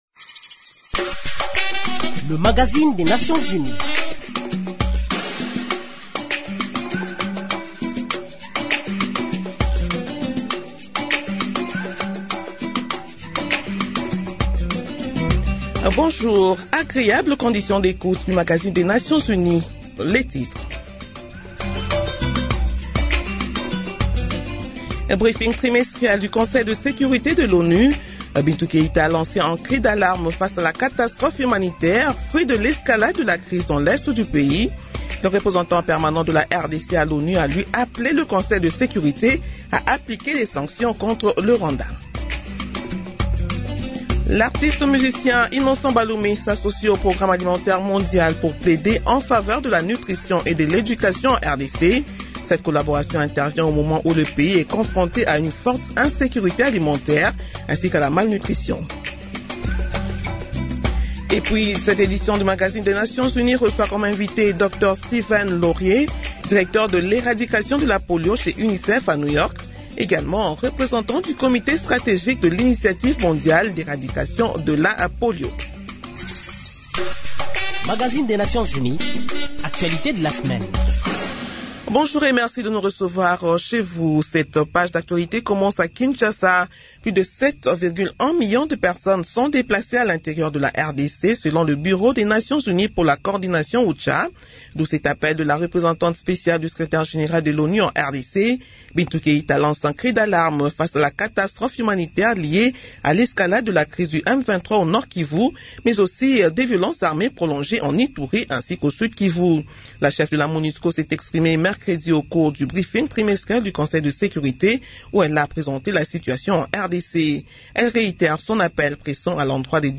Nouvelles en bref